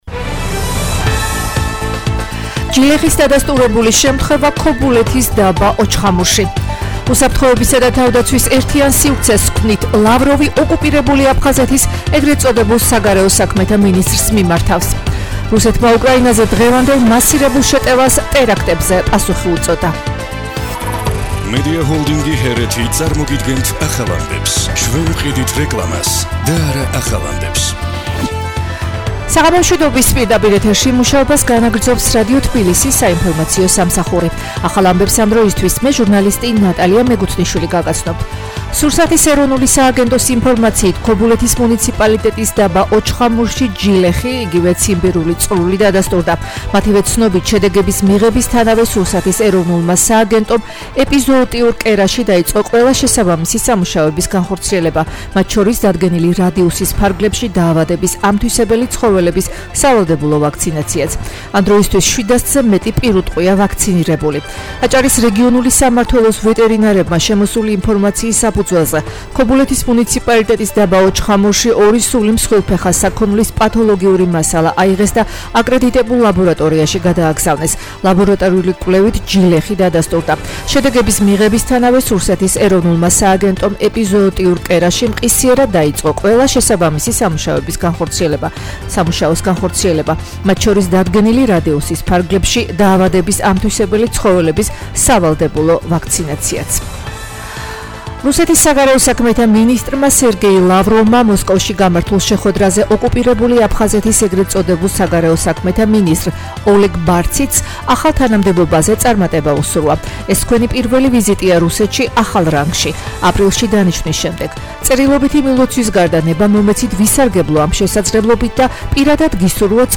ახალი ამბები 17:00 საათზე